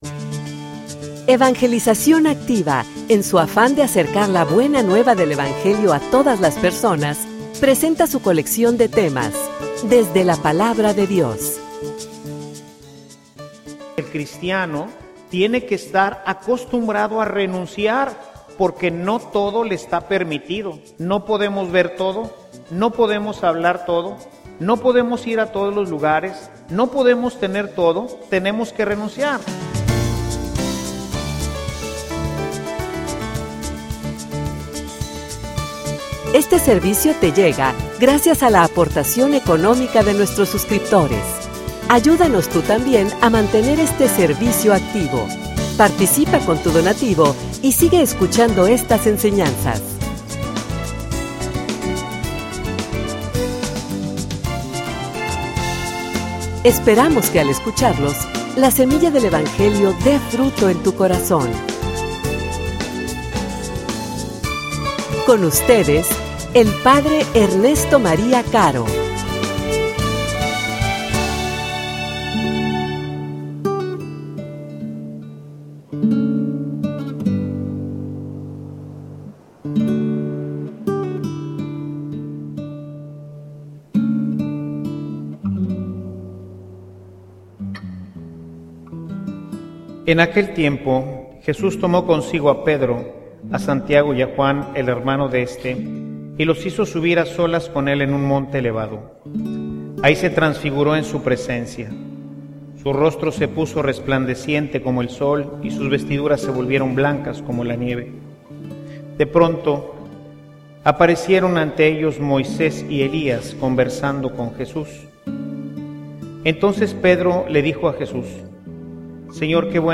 homilia_Hay_que_morir_para_vivir.mp3